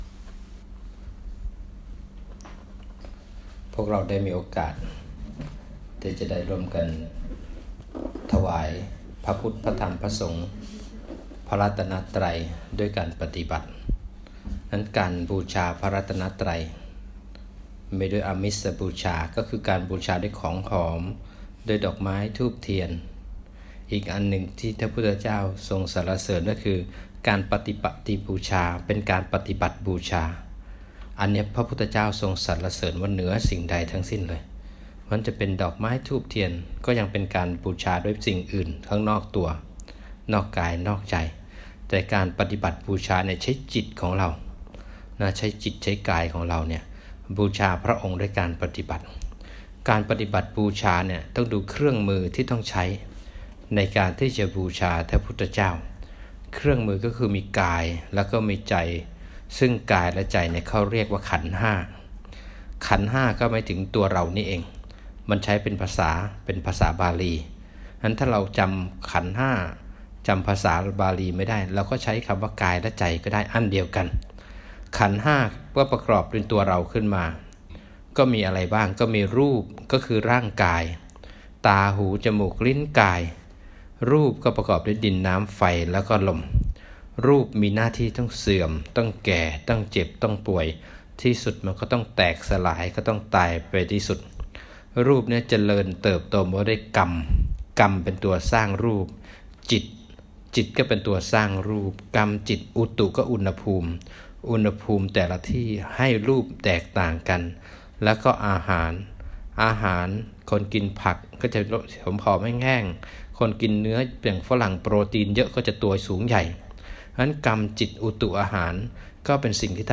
ธรรมบรรยายก่อนเดินทาง